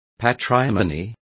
Complete with pronunciation of the translation of patrimony.